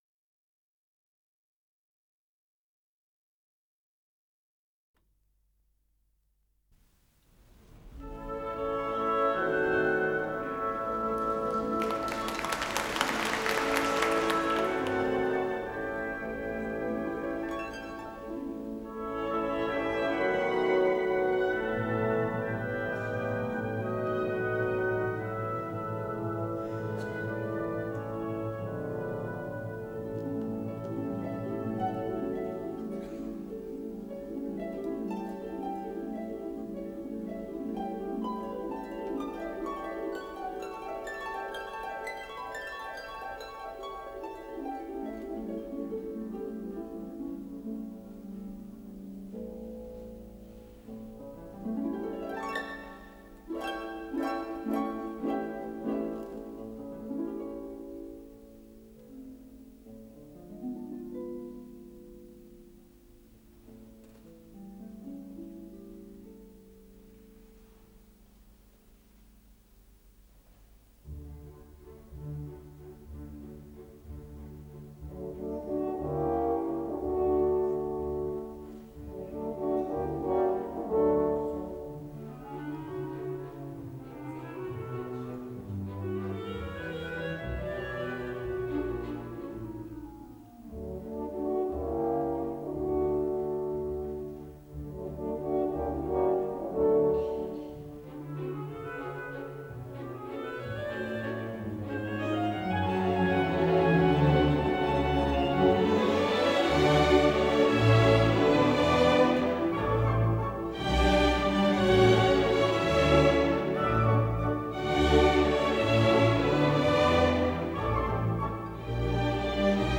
с профессиональной магнитной ленты
ИсполнителиКоролевский филармонический оркестр
Дирижёр - Владимир Ашкенази
Скорость ленты38 см/с